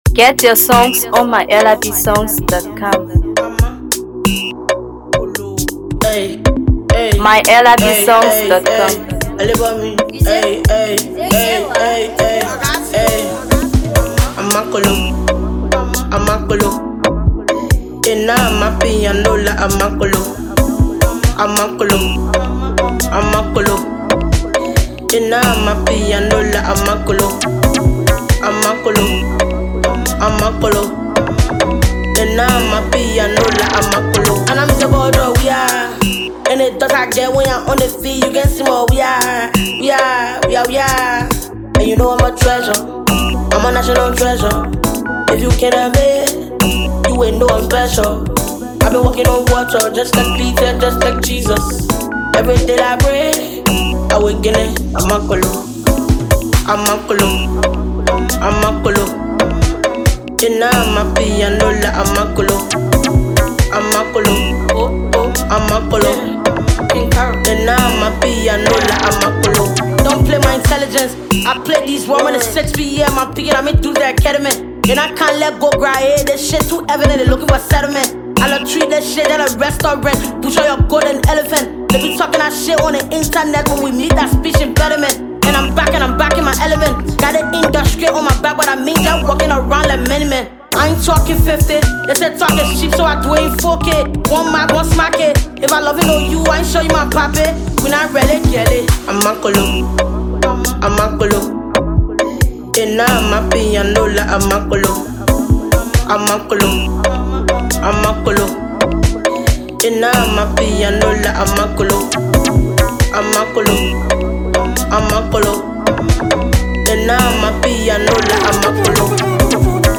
Known for her unique blend of Afrobeat, Dancehall, and Hipco